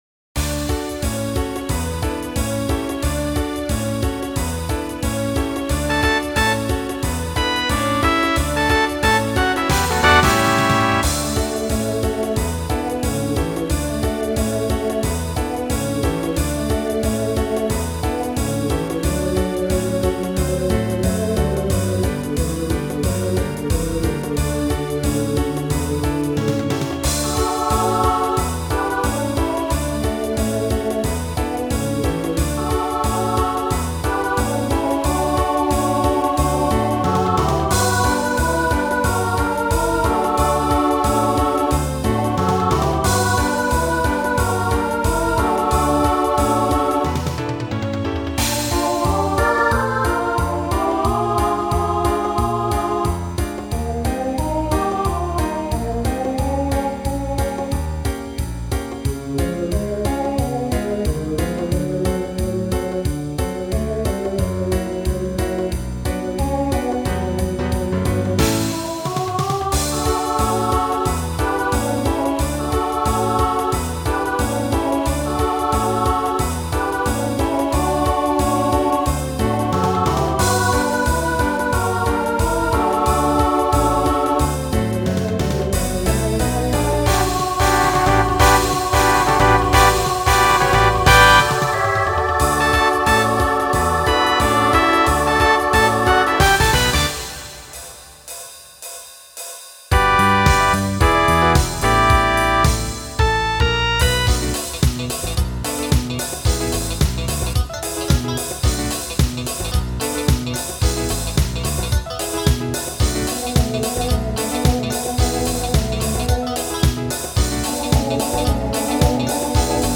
SSA/TTB
Voicing Mixed Instrumental combo Genre Pop/Dance